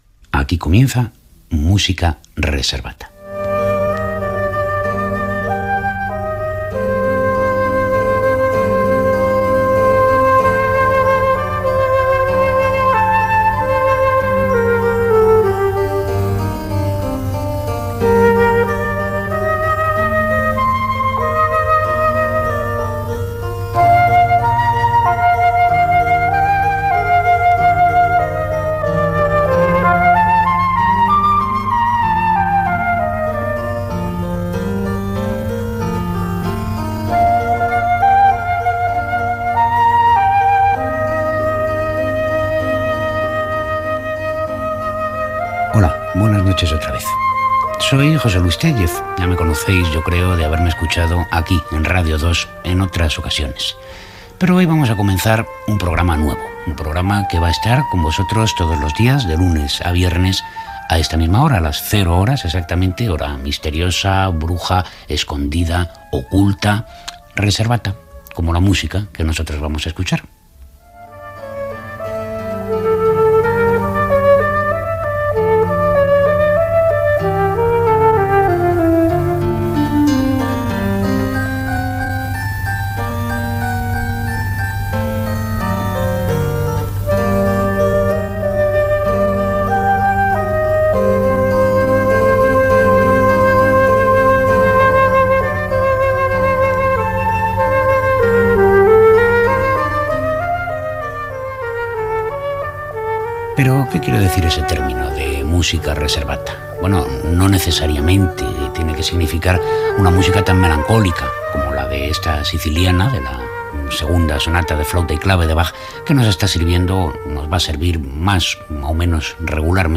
Sintonia i presentació del primer programa explicant el significat de "Música reservata" i tema musical
Musical
FM